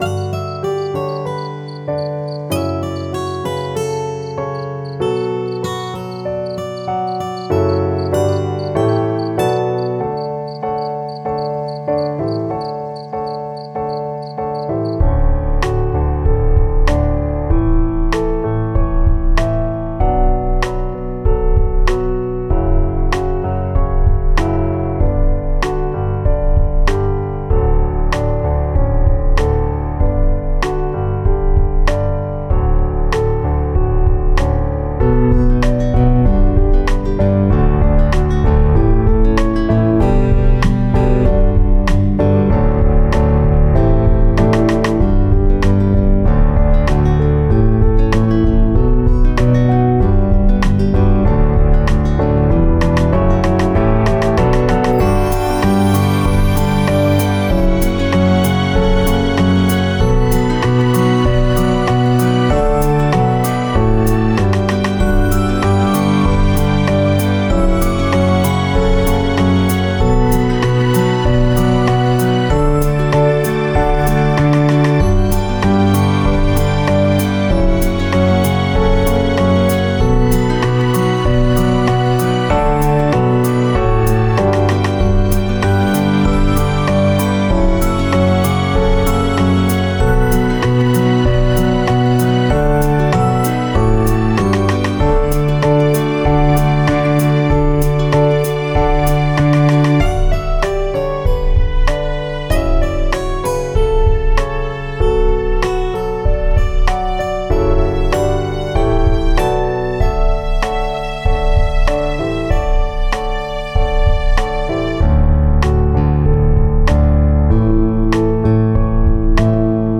BPM：96